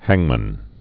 (hăngmən)